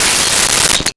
Glass Break 03
Glass Break 03.wav